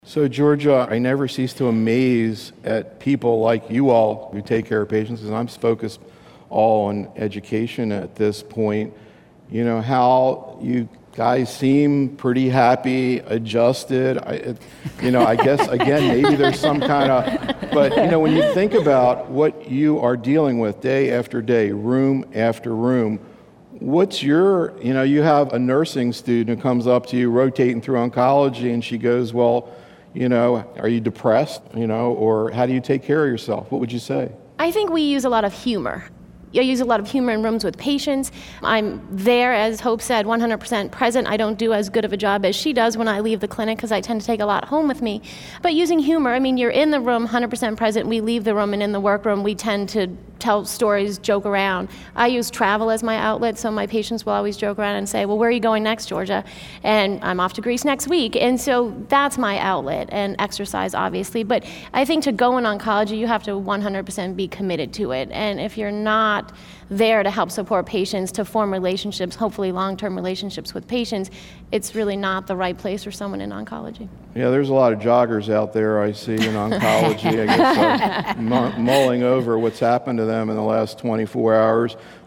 In these audio proceedings from a symposium held in conjunction with the Oncology Nursing Society's 2012 Annual Congress, the invited oncology nursing professionals participating as part of our faculty panel present actual patient cases from their practices, setting the stage for faculty discussion of optimal therapeutic and supportive care strategies in breast cancer.